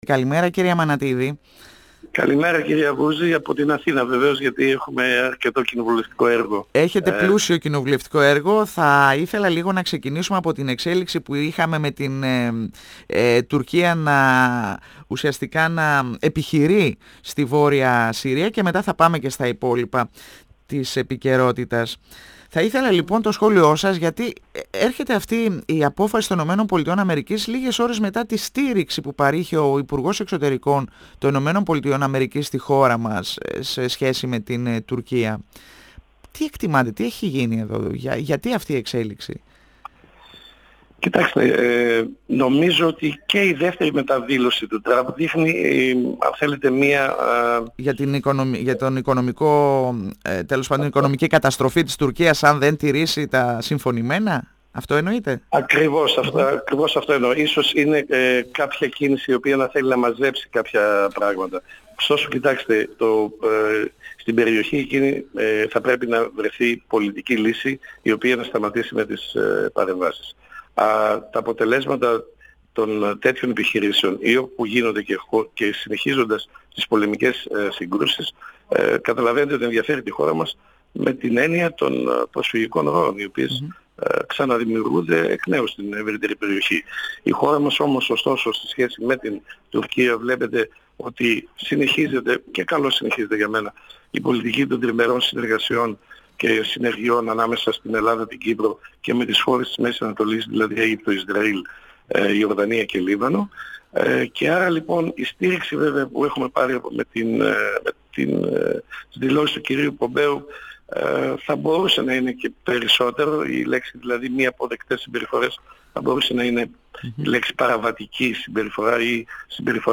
Ο βουλευτής Α’ Θεσσαλονίκης του ΣΥΡΙΖΑ Γιάννης Αμανατίδης στον 102 fm της ΕΡΤ3